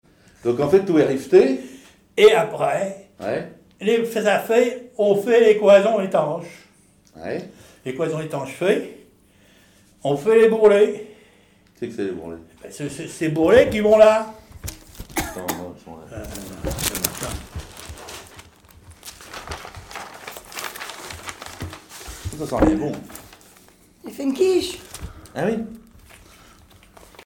Témoignages d'un charpentier naval sur les techniques locales
Catégorie Témoignage